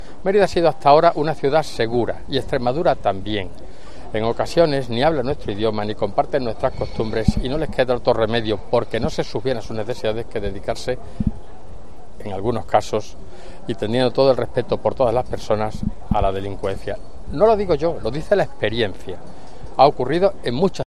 Lo ha dicho el portavoz de Vox en la Asamblea, Ángel Pelayo Gordillo. Una "reprobación" que pedirá tanto en el Ayuntamiento como en la Asamblea de Extremadura.